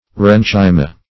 aerenchyma.mp3